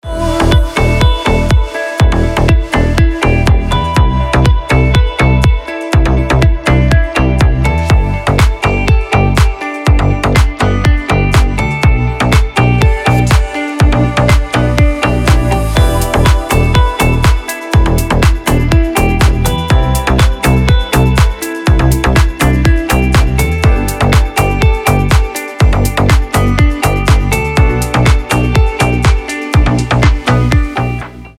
• Качество: 320, Stereo
deep house
легкие
нежные
ремиксы
Стиль: deep house